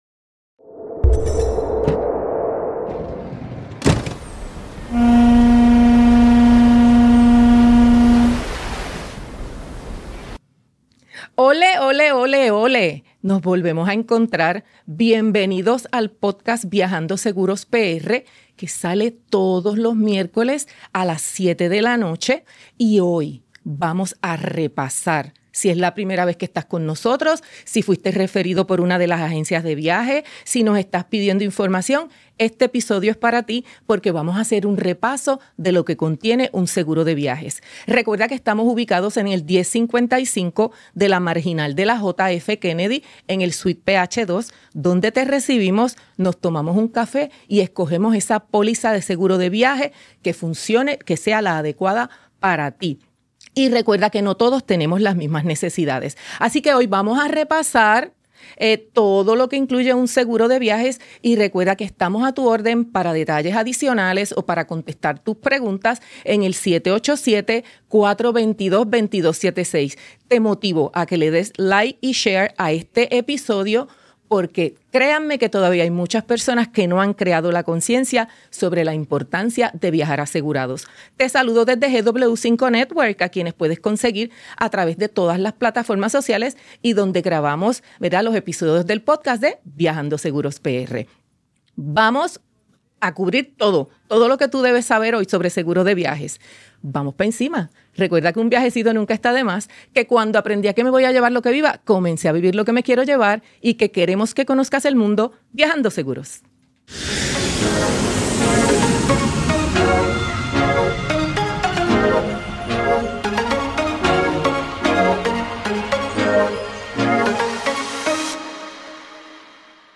Grabados desde GW5 Studios